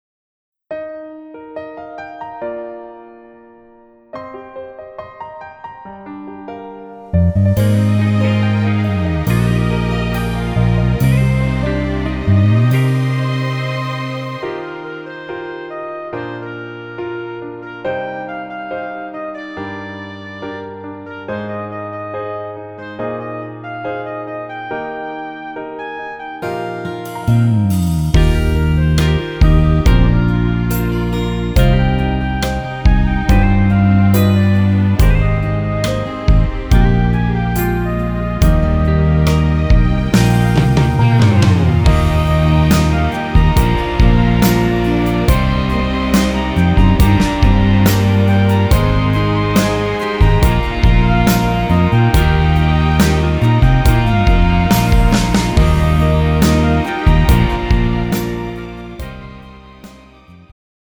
음정 여자키
장르 축가 구분 Pro MR